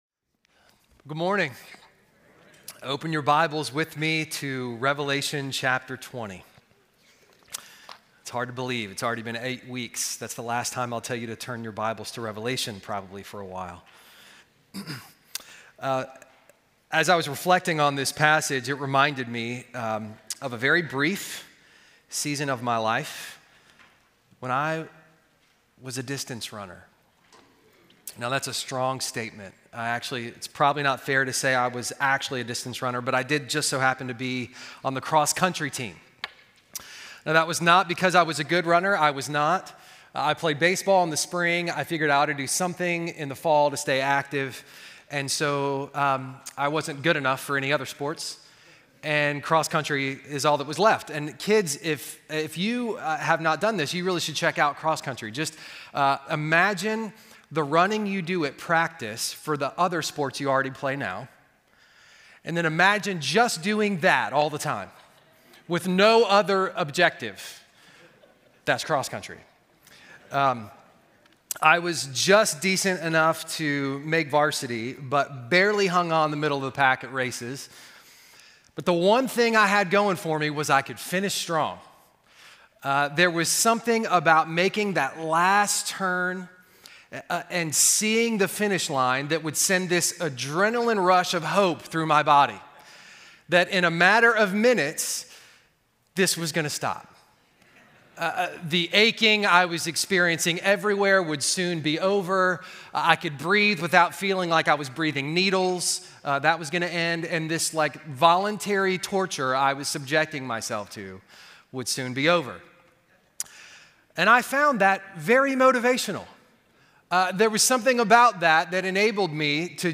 Sermons from Covenant Life Church